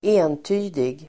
Uttal: [²'e:nty:dig]